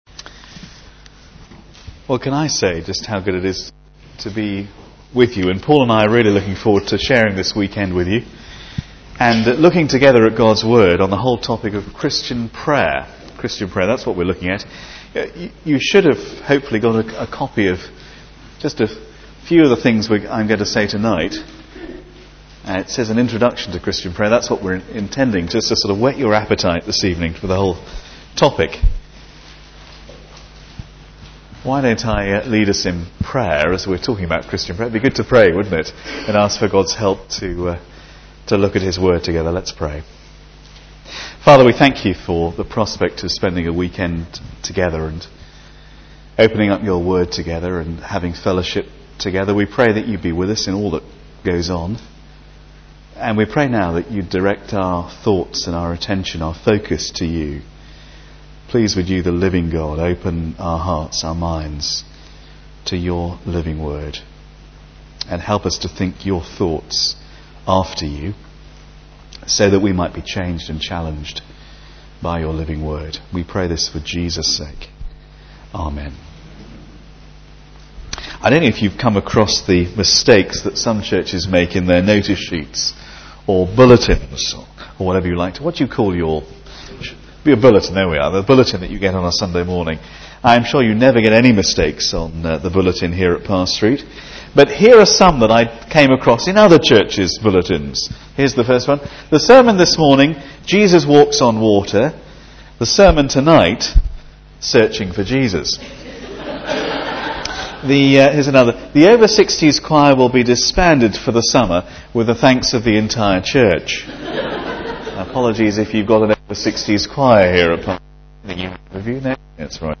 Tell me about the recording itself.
Sermons and Talks recorded at Parr Street Evangelical Church, Kendal, Cumbria, UK